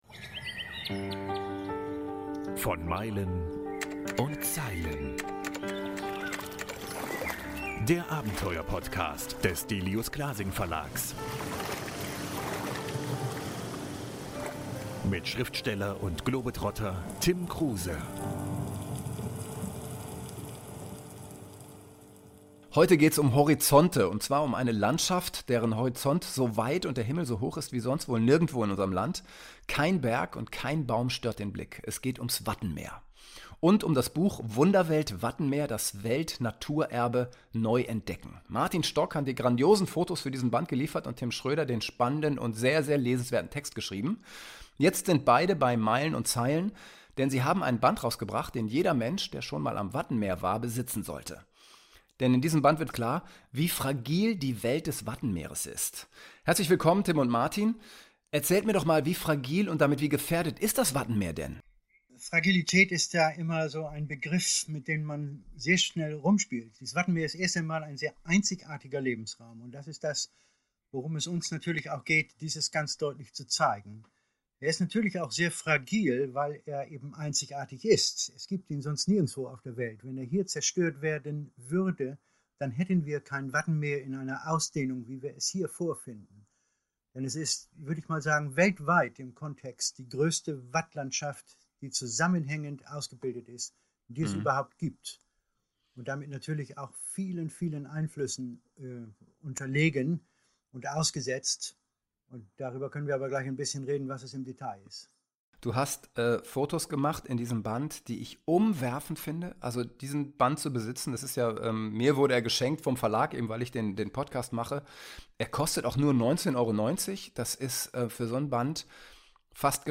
Die Wandlung dieses Lebensraumes im Zuge des Klimawandels ist ebenfalls Thema im Podcast-Interview.